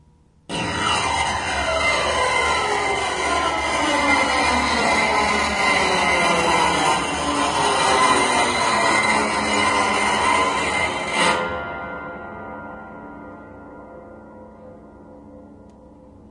SCRAPE S钢琴音板样本 " scrapes10
描述：录音是用2个mxl 990话筒录制的，一个靠近琴弦，另一个在8英尺远的地方，用不同的方法处理。
这些都是立体声录音，但一个通道是近处的话筒，另一个是远处的话筒，所以为了获得最佳效果，可能需要进行一些相位和平移调整。
Tag: 音响 效果 FX 恐怖 工业 钢琴 音响 声音效果 音板